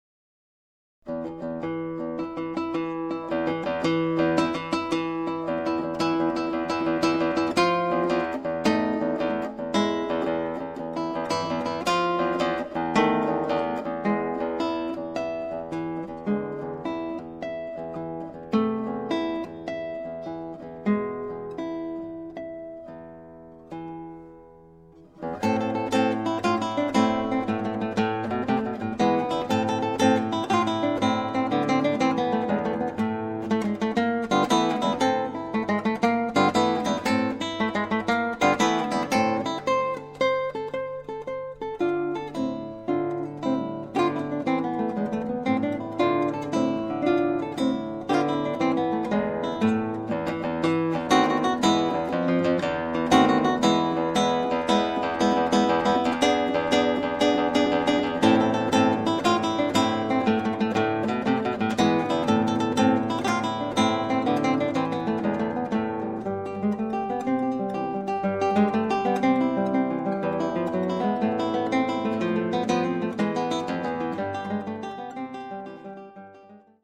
classical guitarist, specializing in Latin and Spanish guitar.